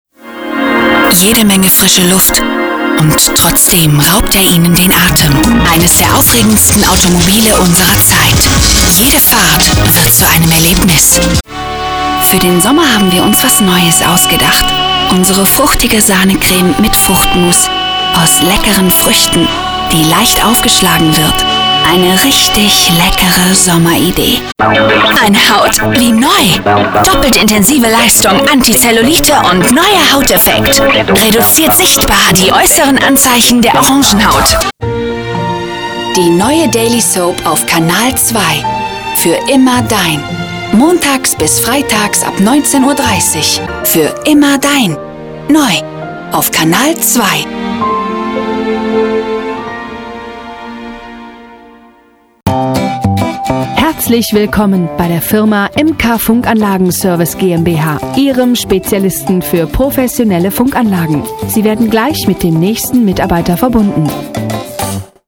Stimmcharakter: erotisch/sexy, verführerisch, dramatisch, dunkel-warm, werblich, jung, älter oder reif, exklusiv, elegant, sympatisch, souverän, geheimnisvoll, sachlich/kompetent, erzählerisch, mütterlich, verrückt/aufgedreht, selbstbewusst, reif und weitere diverse Rollen.
Sprecherin, Profisprecherin
Sprechprobe: Werbung (Muttersprache):